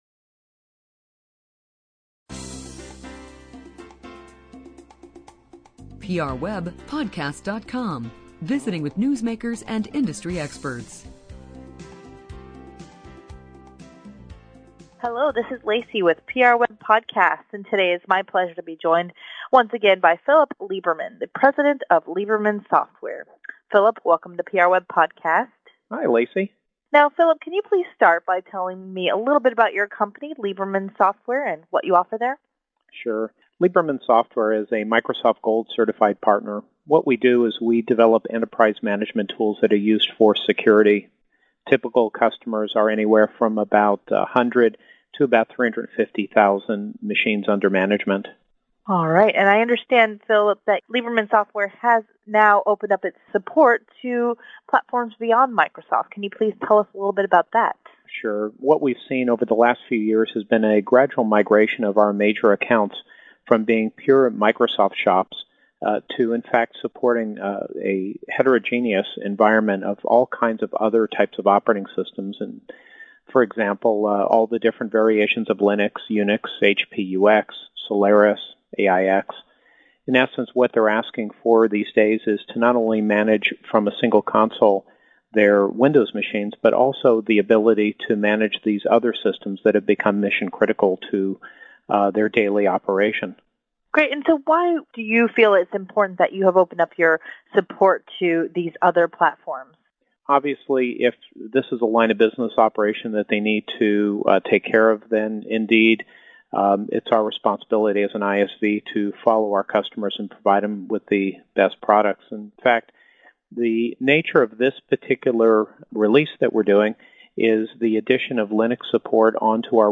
Every day we select and conduct brief interviews around the top press releases at PRWeb.